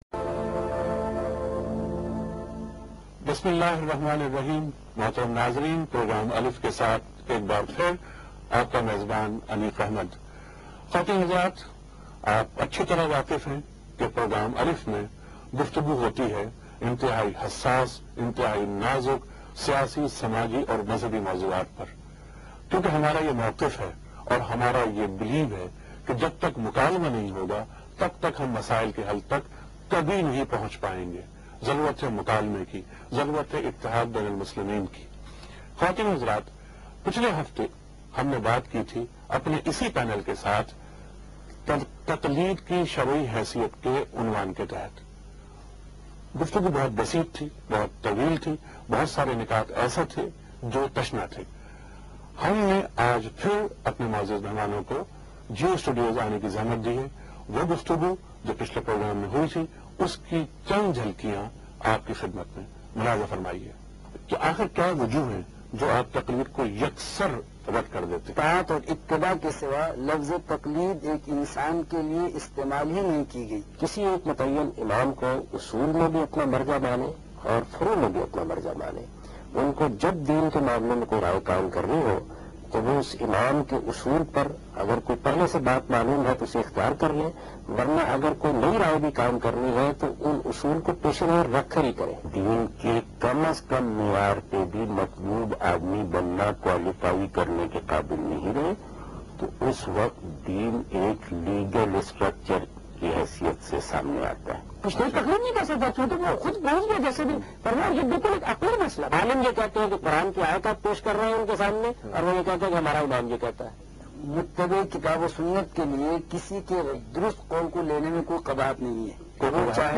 Category: TV Programs / Geo Tv / Alif /